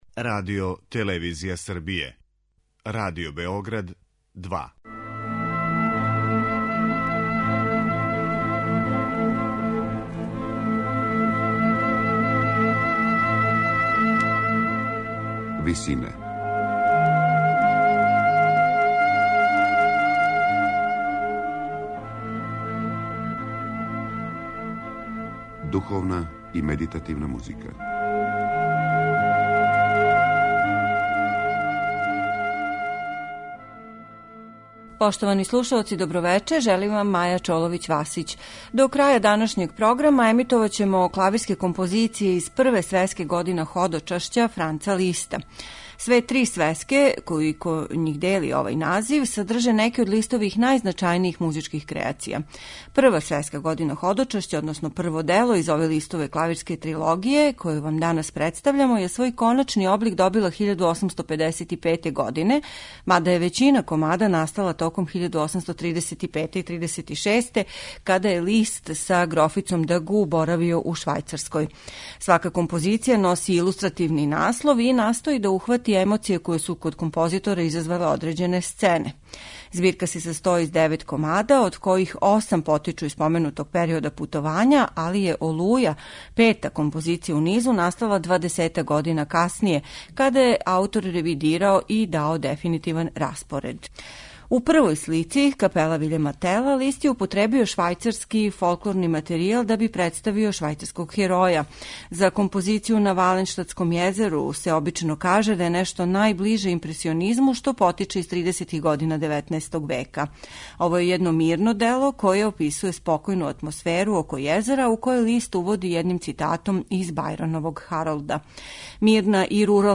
Већина комада из првог дела познате клавирске трилогије „Године ходочашћа", коначно уобличеног 1855. године, настала је много раније, током 1835/36. године. Девет комада овог циклуса који представљају, у музику преточене, утиске композиторовог путовања по Швајцарској слушаћете у интепретацији пијанисте Алфреда Брендла.